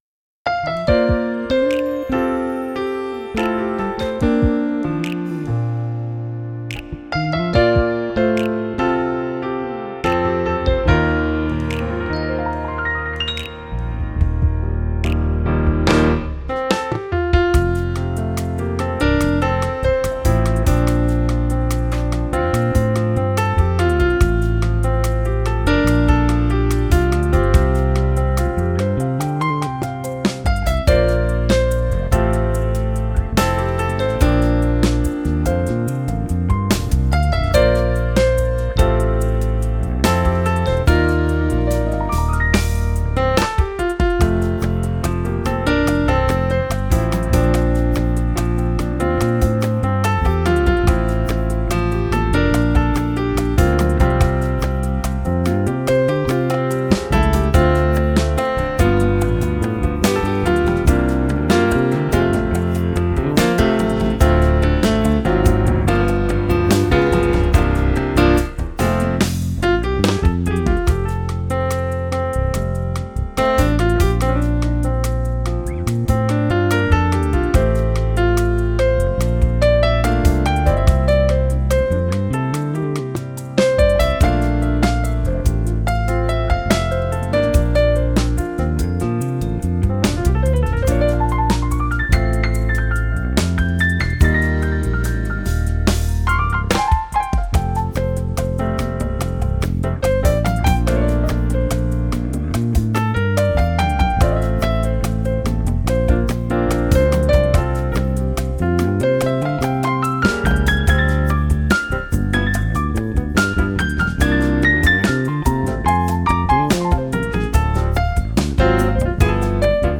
Deeply sampled grand piano with a rich, versatile tone.